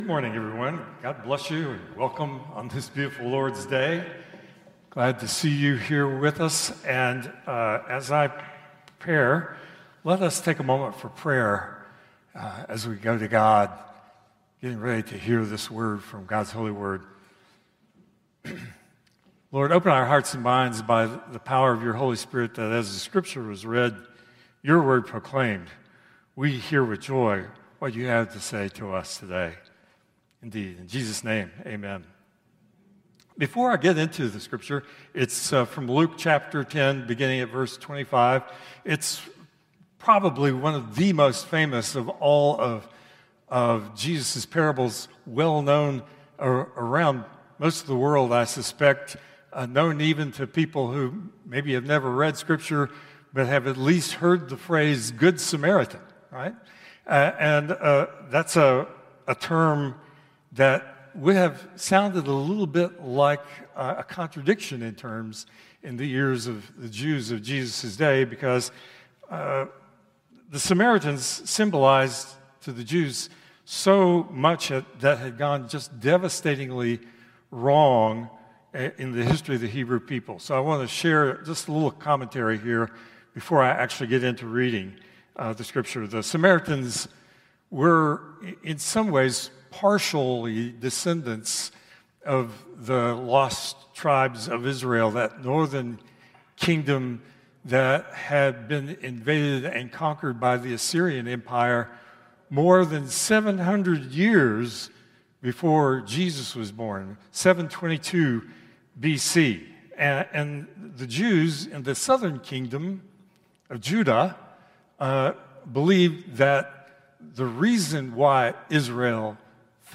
Traditional Service 3/23/2025